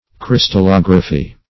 Crystallography \Crys`tal*log"ra*phy\
(kr[i^]s"tal*l[o^]g"r[.a]*f[y^]), n. [Gr. kry`stallos crystal